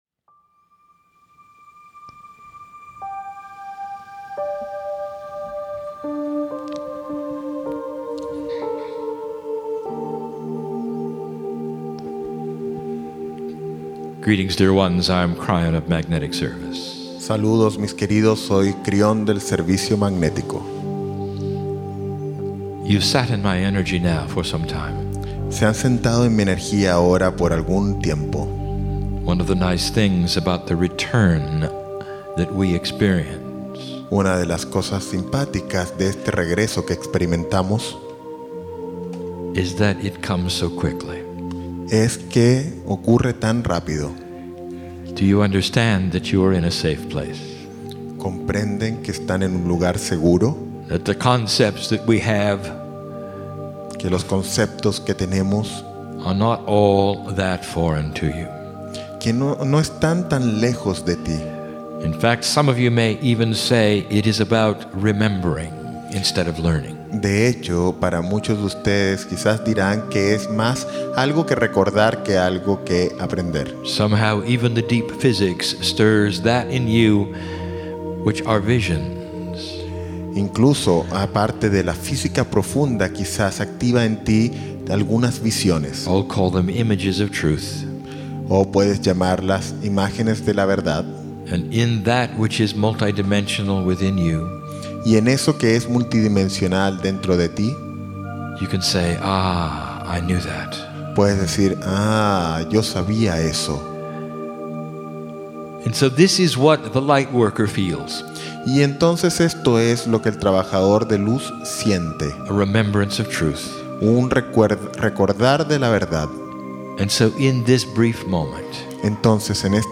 Back The Kryon Patagonia Cruise January/February 2012 Seven
Day One Mini - January 25 - Mini channelling before the main 2.